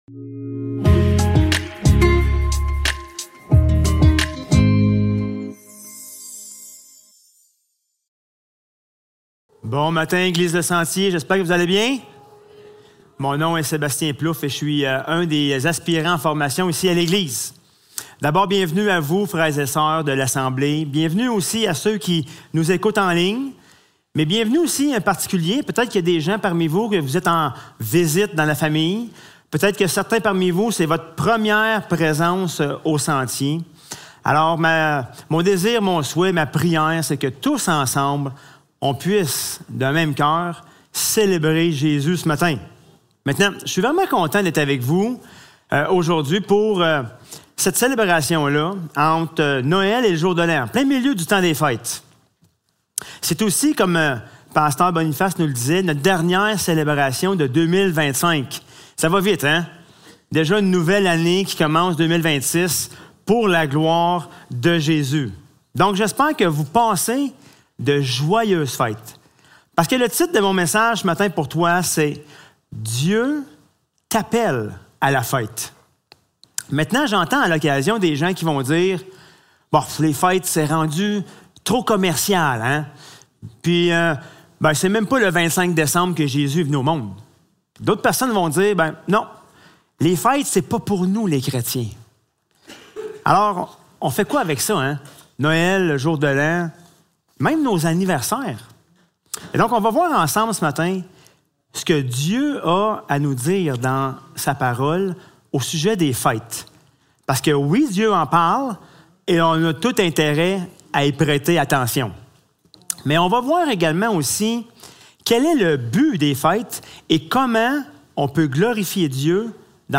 Lévitique 23.1-2 Service Type: Célébration dimanche matin Description